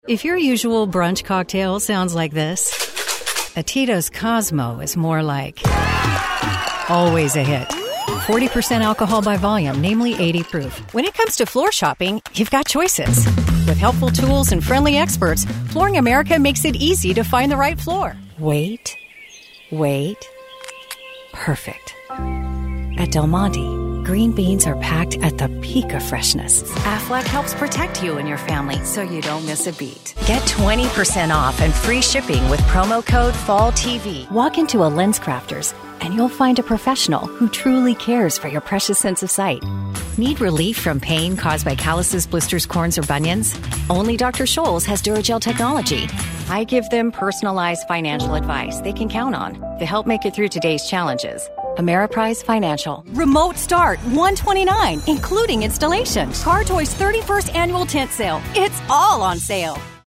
Trustworthy
Warm
Authoritative